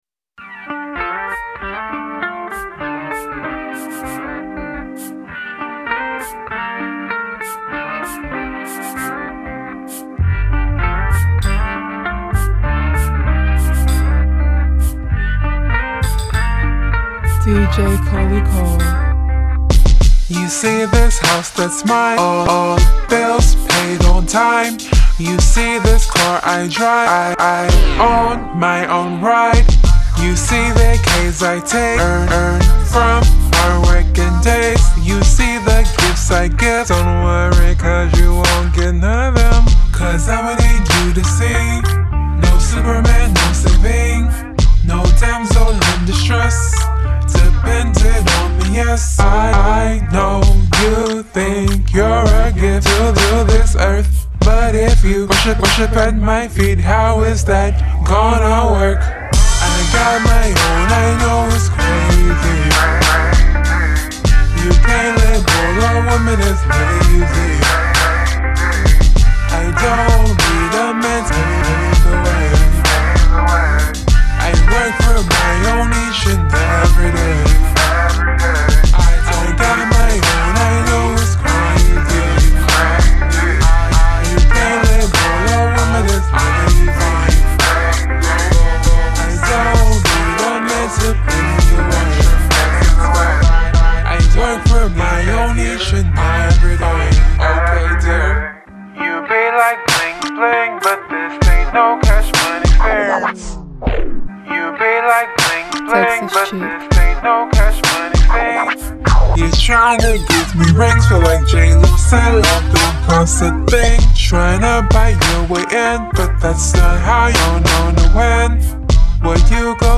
Genre: Urban Pop